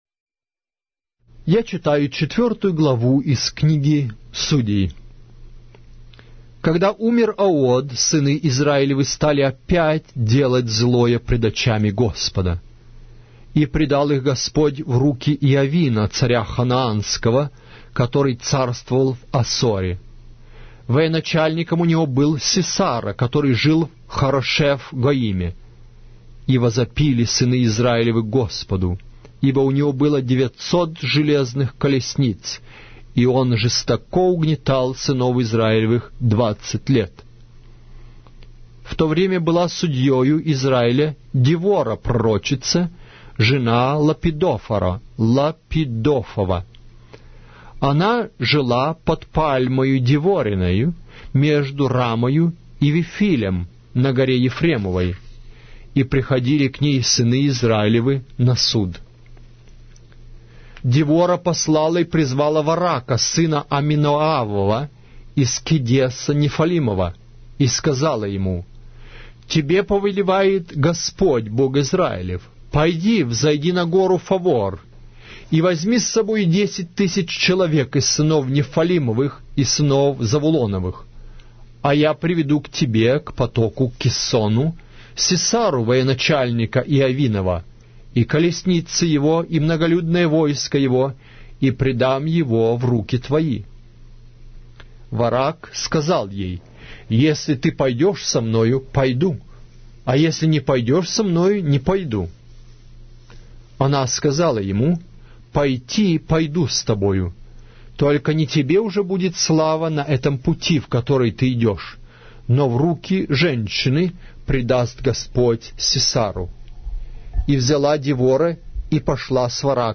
Аудиокнига: Книга Судей Израилевых